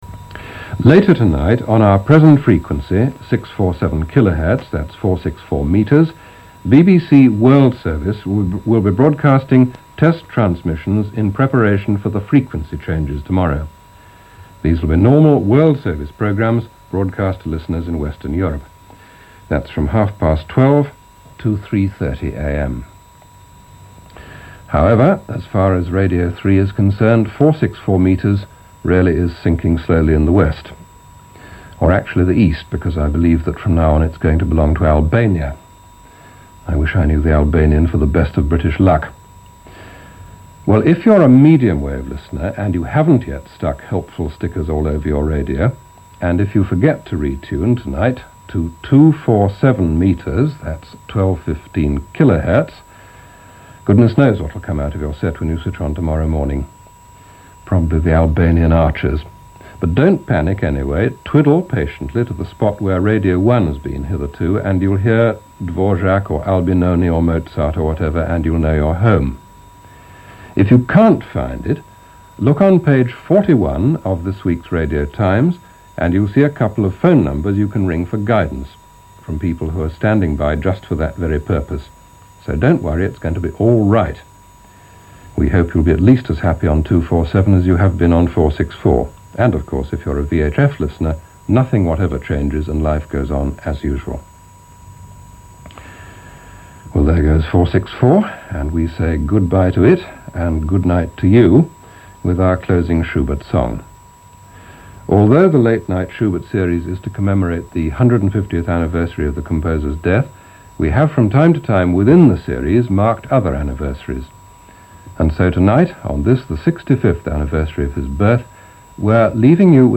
Many BBC stations shifted around at the same time, meaning Radio3 moving to 1215 kHz, which had been hitherto occupied by Radio 1. Relish in this beautifully-delivered, puzzled continuity as the station prepared to switch.